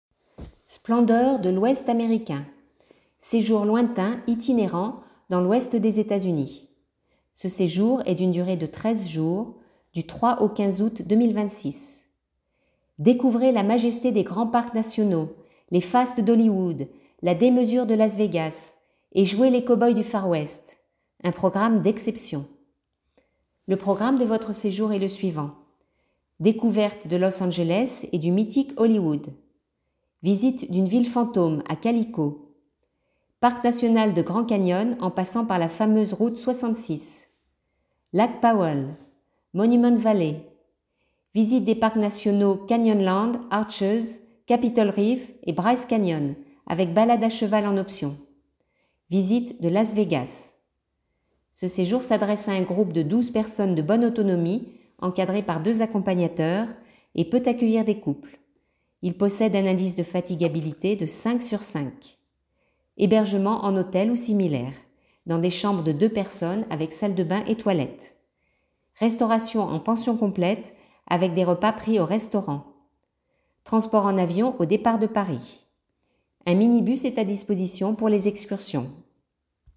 Présentation audio du séjour